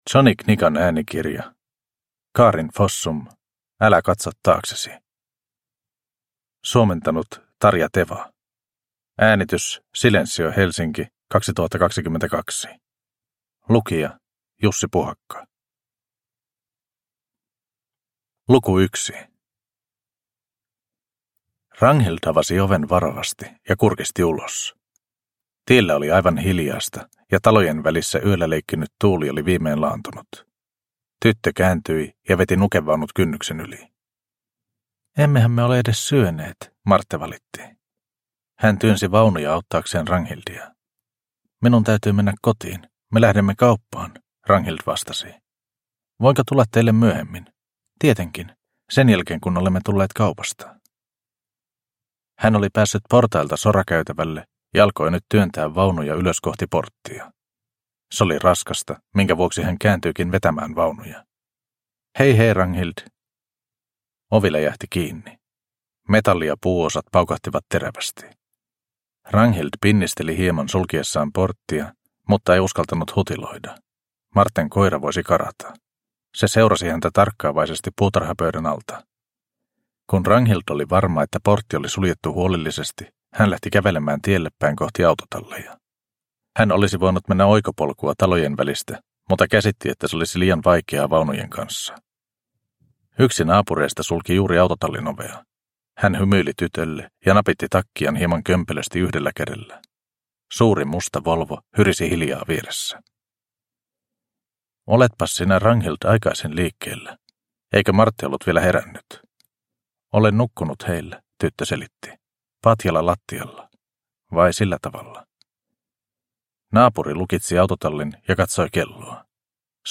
Älä katso taaksesi! – Ljudbok – Laddas ner